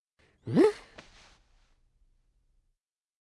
avatar_emotion_shrug.ogg